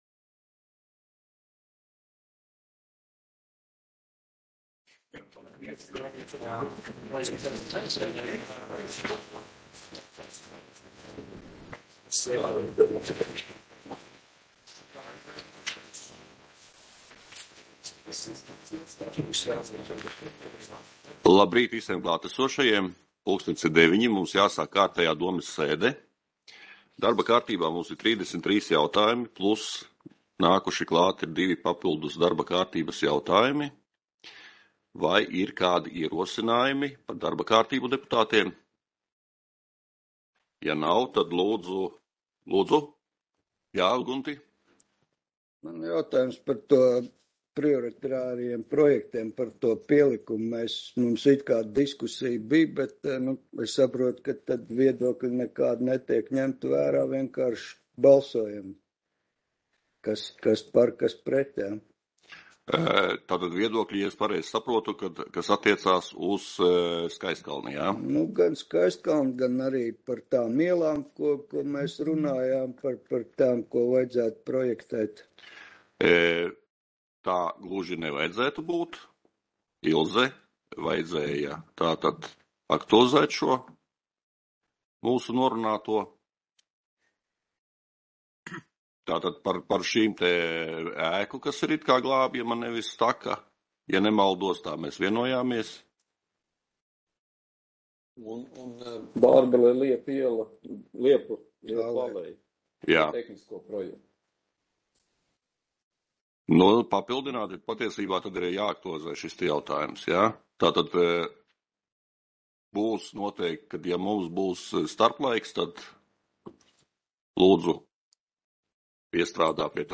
Audioieraksts - 2022.gada 28.aprīļa domes sēde